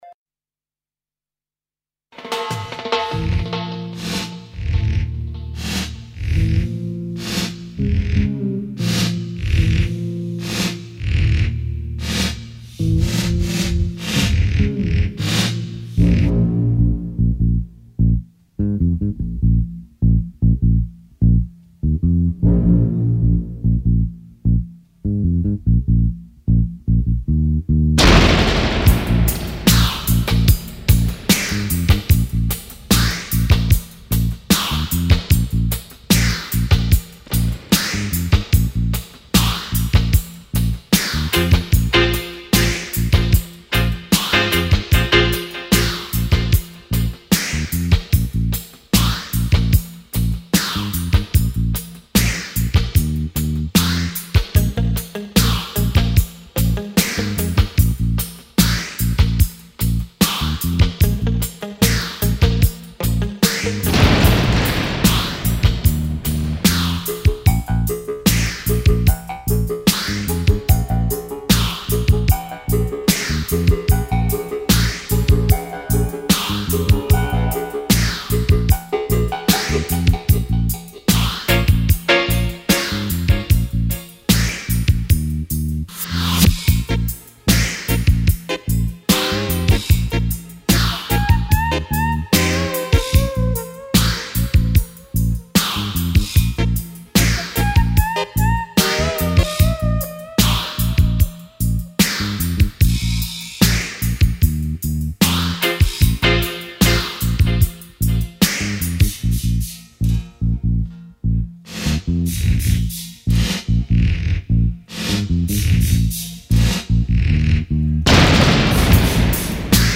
Il sabato del villaggio... una trasmissione totalmente improvvisata ed emozionale. Musica a 360°, viva, legata e slegata dagli accadimenti.